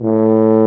BRS TUBA F0I.wav